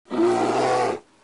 Braunbär Geräusche
Braunbaer-Geraeusche-Wildtiere-in-Deutschland-Universfield-Pixabay.mp3